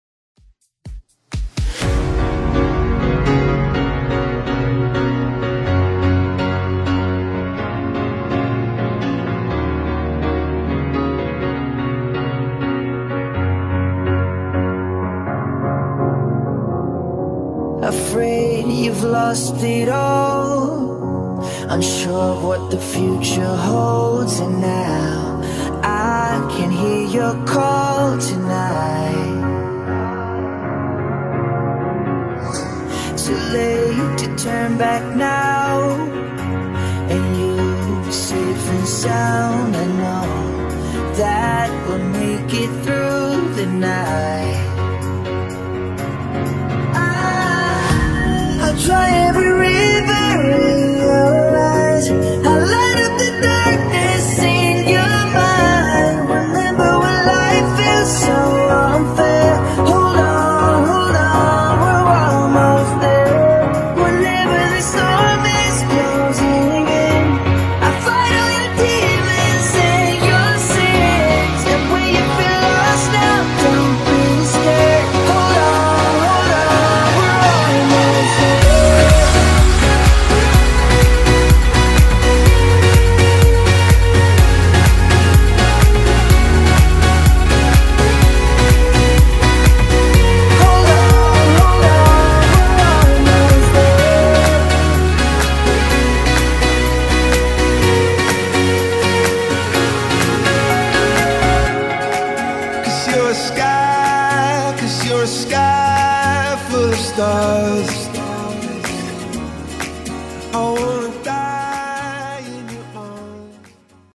Genre: BOOTLEG Version: Clean BPM: 126 Time